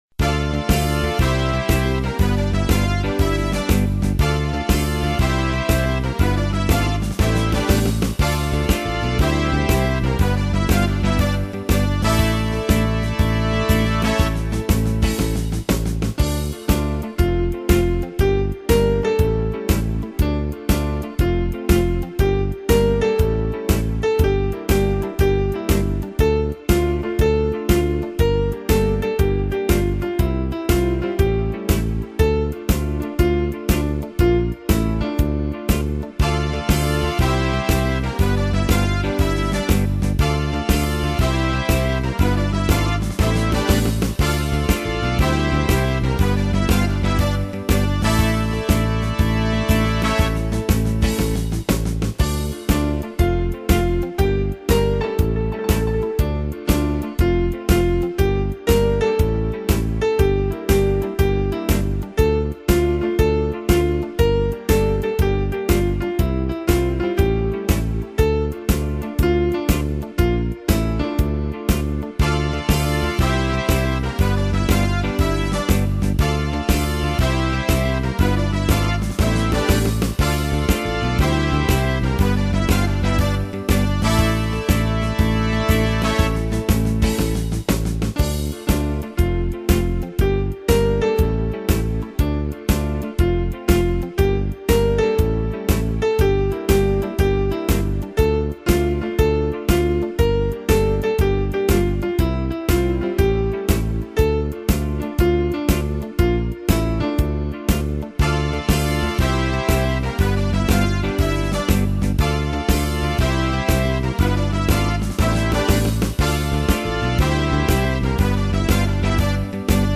Harde popmuziek.wma